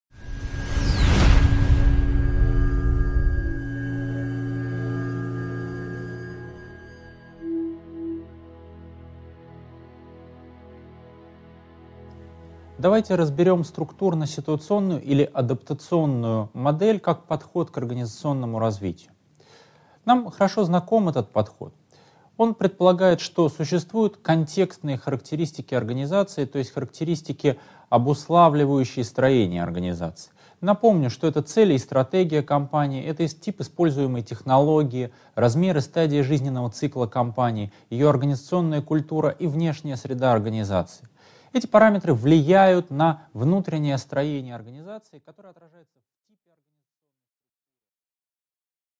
Аудиокнига 9.3. Структурно-ситуационная модель | Библиотека аудиокниг